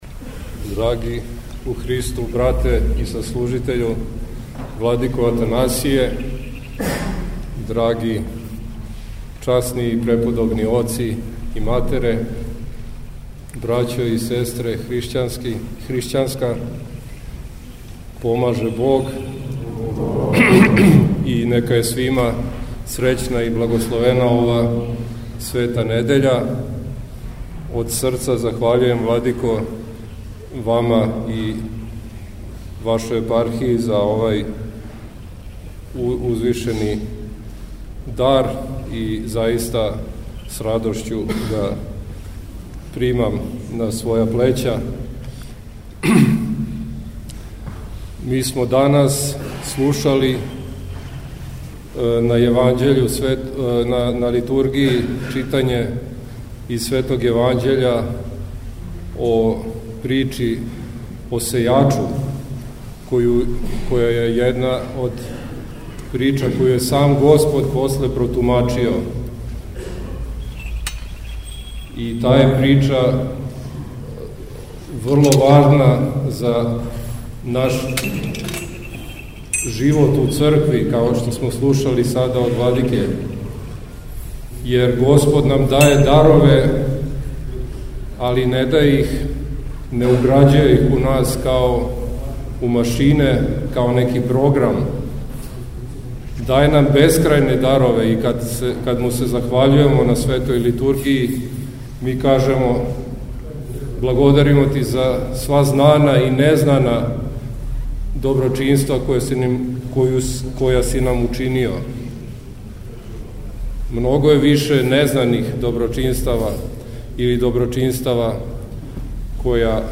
У недељу 17. новембра 2024. године велики број верног народа се сабрао код храма Светог Николаја Српског у Коловрату код Пријепоља да дочека мошти Светог Николаја и да учествује у Божанској Литургији којом је началствовао Преосвећени Епископ Исихије уз саслужење Високопреосвећеног Митрополита Атанасија и више свештенослужитеља двеју Епархија.